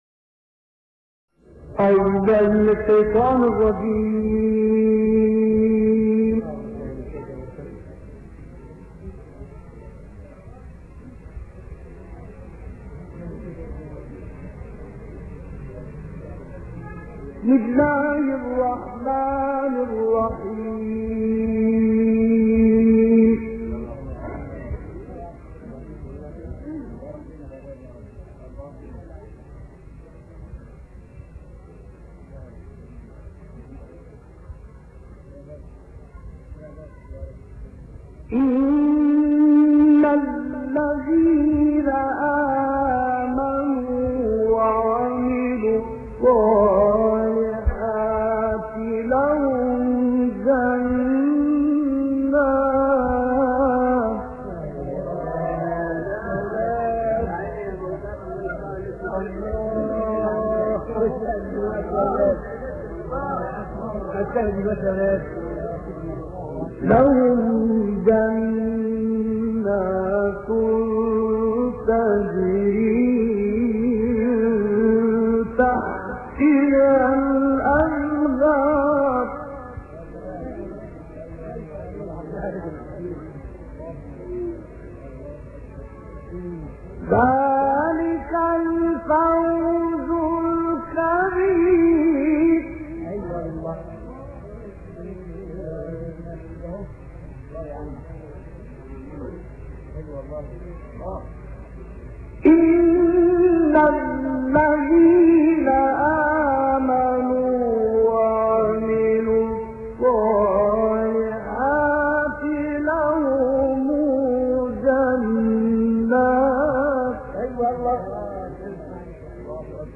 تلاوت سوره‌های بروج و طارق با صدای عبدالعزیز حصان + دانلود
گروه فعالیت‌های قرآنی: خبرگزاری ایکنا با همکاری شبکه رادیویی قرآن، شاهکاری از تلاوت مجلسی استاد «حصان» ملقب به ملک الوقف و الإبتدا از سوره‌های بروج، طارق و اعلی را ارائه می‌دهد.